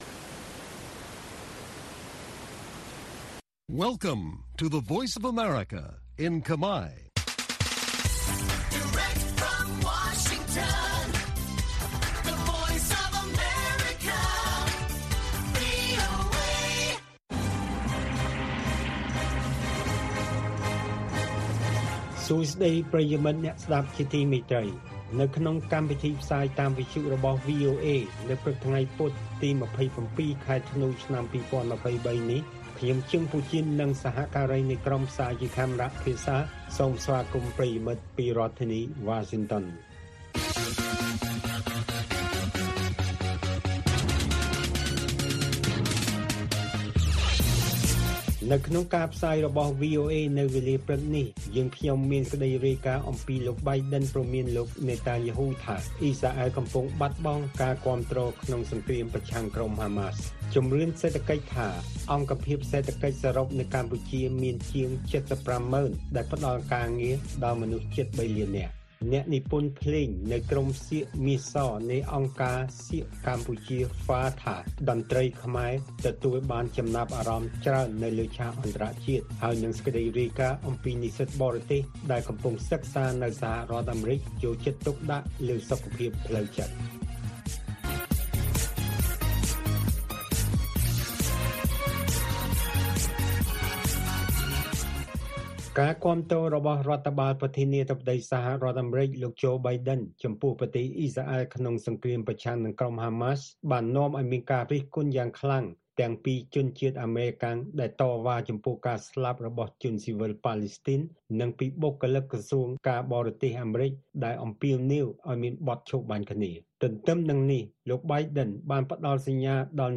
ព័ត៌មានពេលព្រឹក ២៧ ធ្នូ៖ ជំរឿនសេដ្ឋកិច្ច៖ អង្គភាពសេដ្ឋកិច្ចសរុបនៅកម្ពុជាមានជាង៧៥ម៉ឺន ដែលផ្តល់ការងារដល់មនុស្សជិត៣លាននាក់